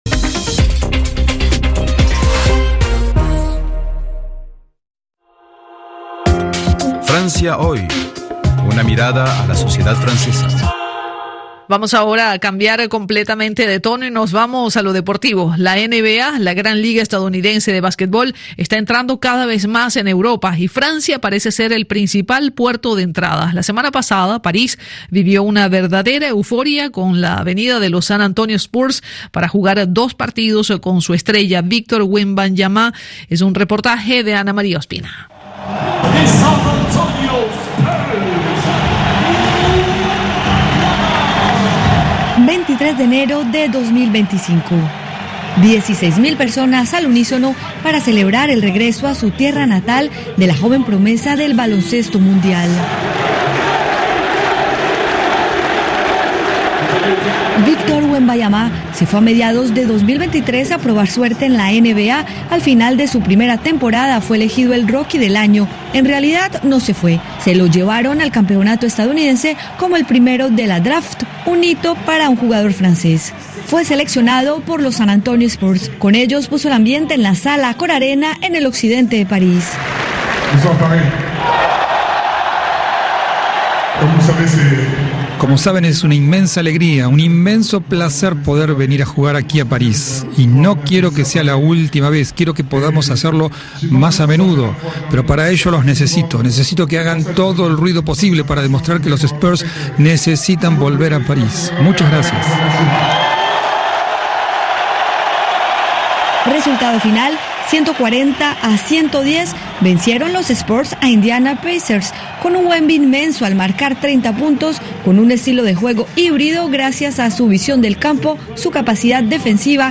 Noticieros